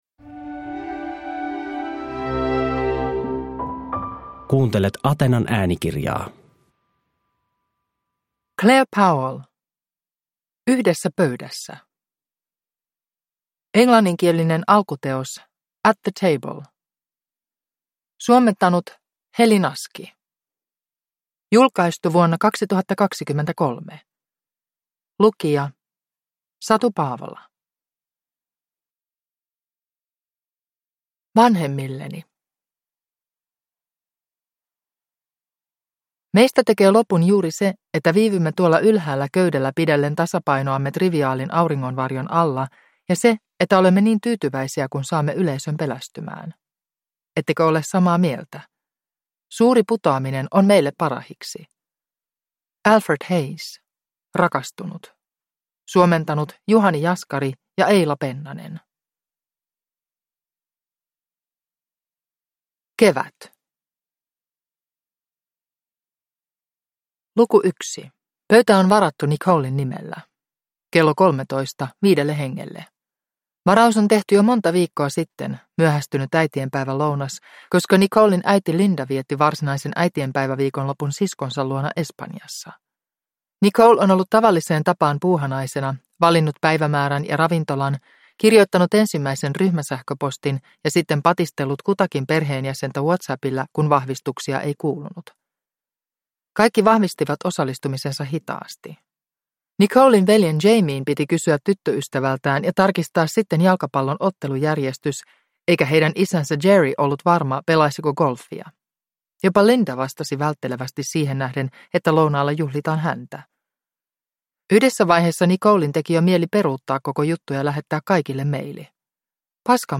Yhdessä pöydässä – Ljudbok